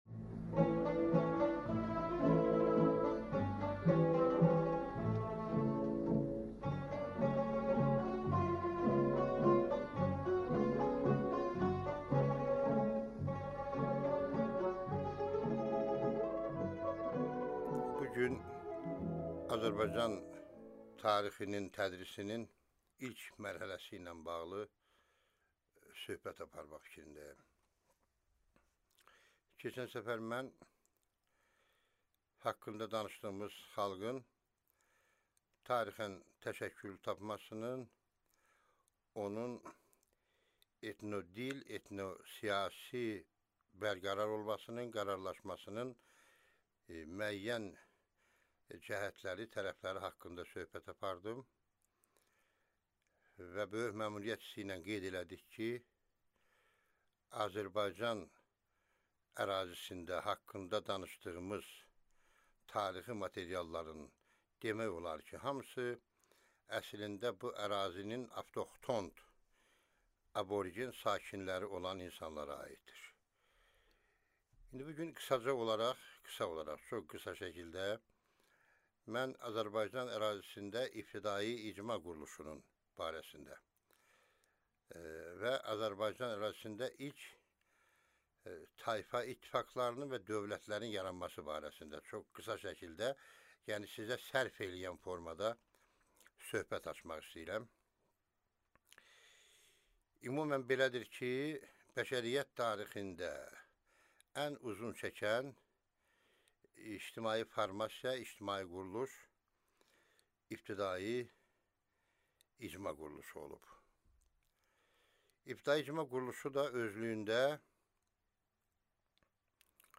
Аудиокнига Azərbaycan ərazisində ibtidai icma quruluşu | Библиотека аудиокниг